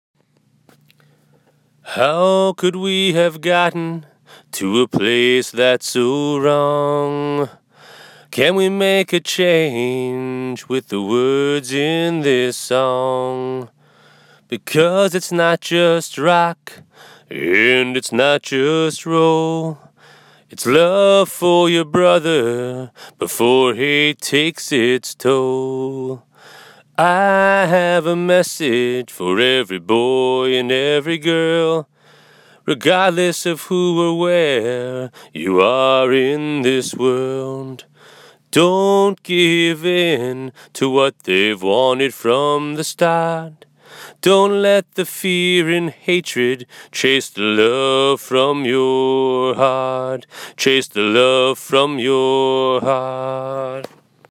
awesome a cappella version
Verse
I sense a bit of a country tone in this 🙂
Better-way-Verse-Melody.m4a